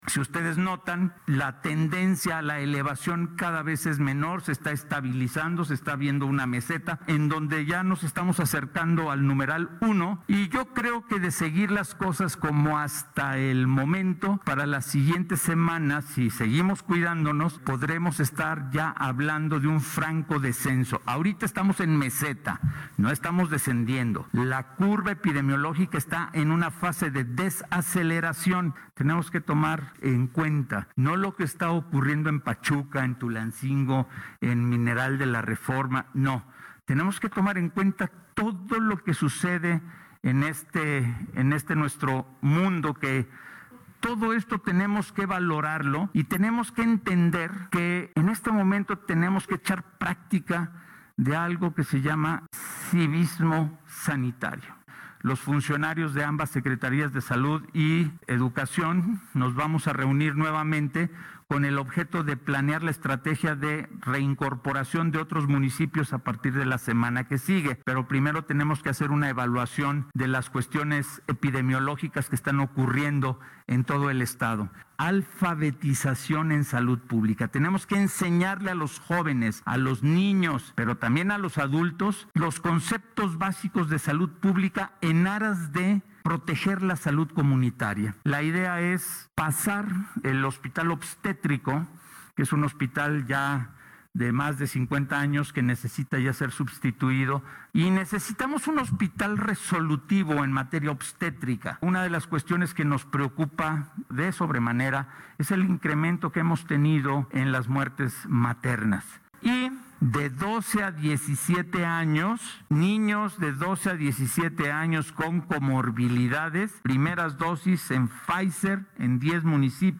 Pachuca, Hidalgo., a 25 de octubre de 2021.- Será en los siguientes días cuando en coordinación con autoridades educativas, se determine cuantos municipios se sumarán a los 42 que ya retornaron a clases presenciales, así lo expuso ante medios de comunicación, el titular de la Secretaría de Salud Estatal (SSH), Alejandro Efraín Benítez Herrera, al presentar el balance semanal de las condiciones actuales de la Entidad ante la pandemia.
Alejandro-Efrain-Benitez-Herrera-supervision.mp3